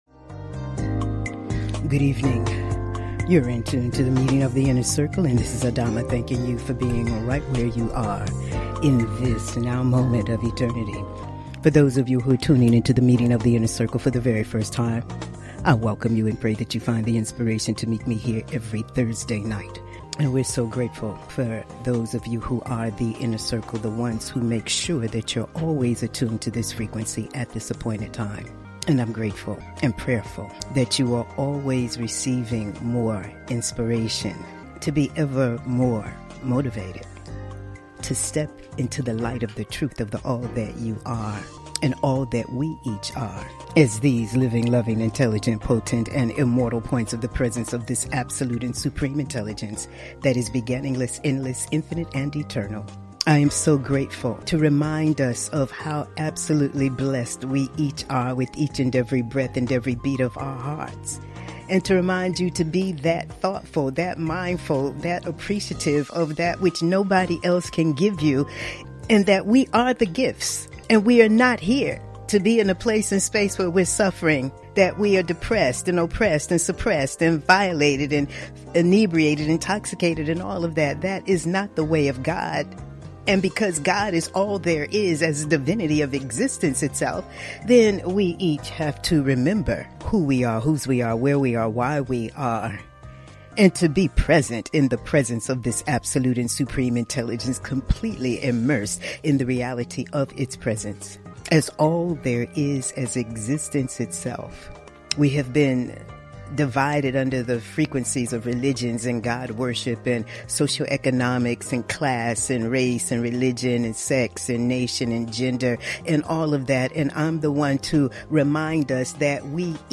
Monologues